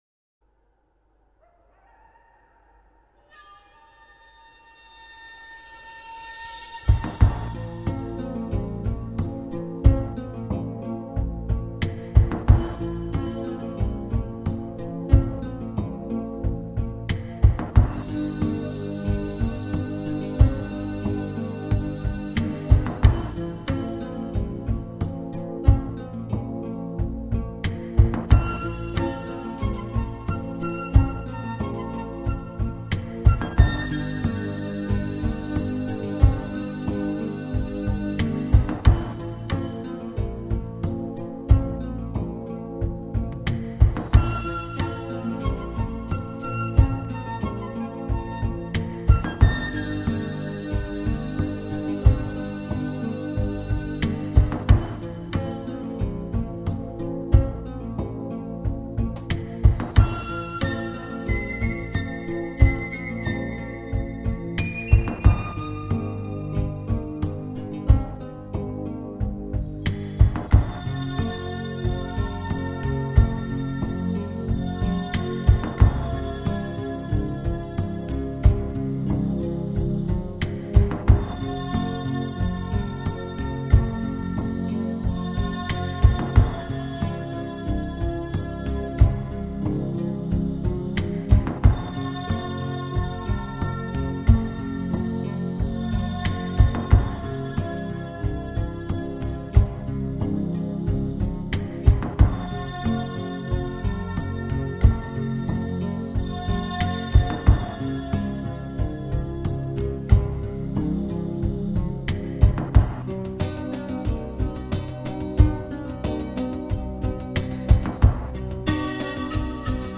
全碟大量的使用了直笛和排萧的吹奏，键盘及敲击乐器等传统南美乐器，加上隐隐的和声，揉出一派纯真的南美风格。